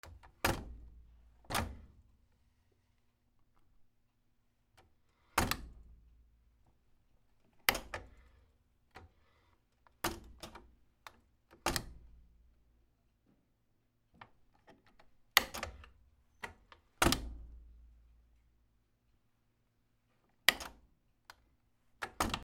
/ M｜他分類 / L10 ｜電化製品・機械
脱水機